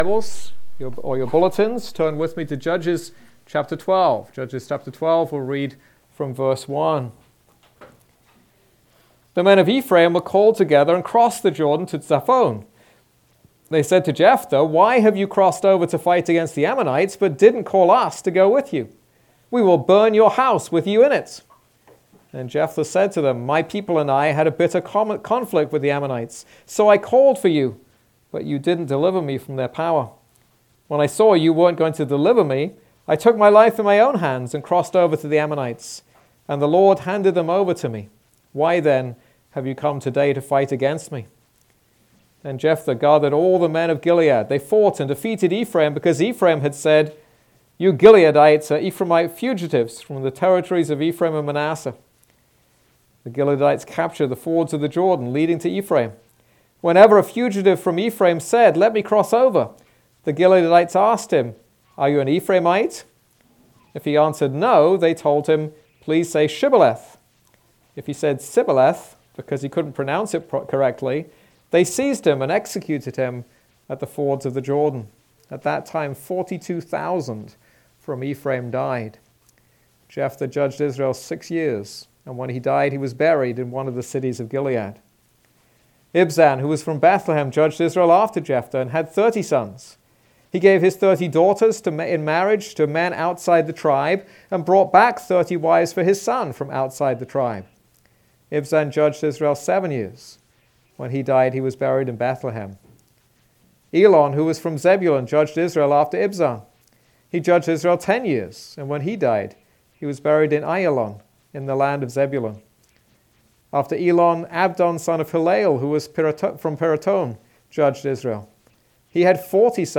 This is a sermon on Judges 12.